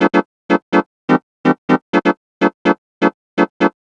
cch_synth_loop_romance_125_Am.wav